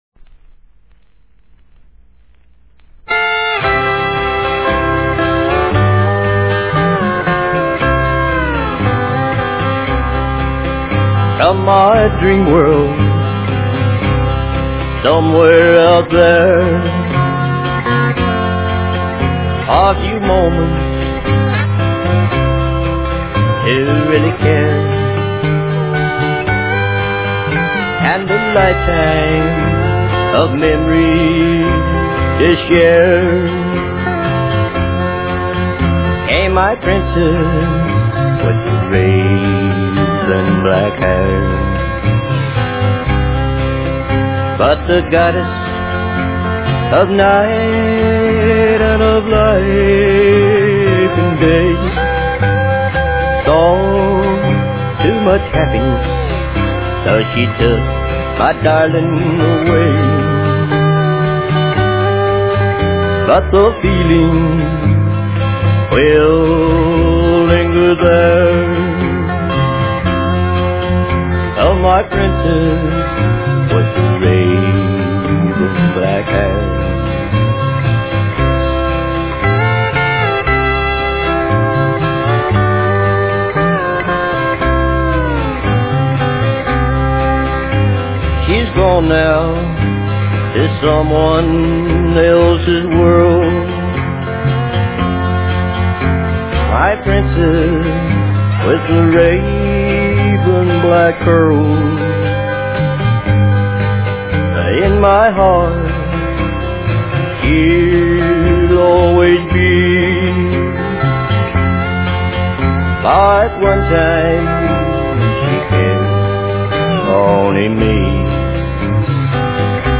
A Tribute To Old Time Country Music
guitar and fiddle player